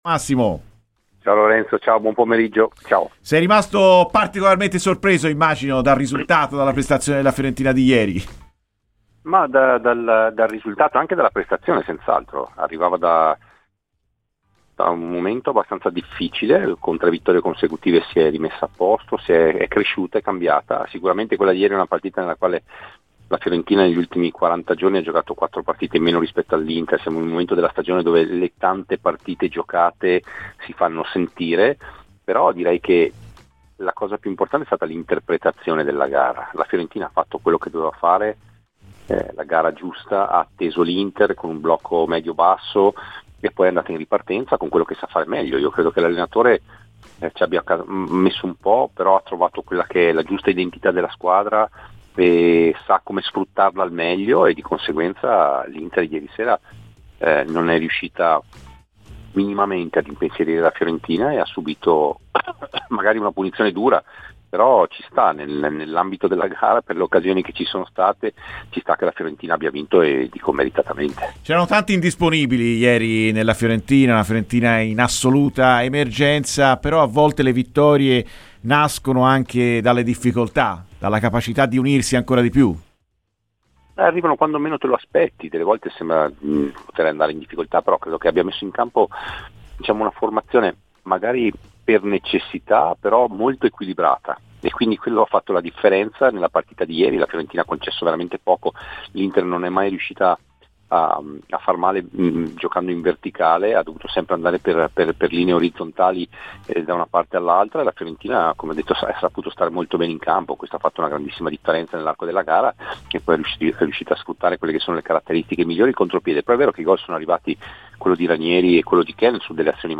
Ai microfoni di Radio FirenzeViola l'ex difensore nerazzurro Massimo Paganin si è espresso così sulla sorprendente sconfitta dell'Inter, nel recupero contro la Fiorentina: "Sono stato sorpreso dal risultato e dalla prestazione.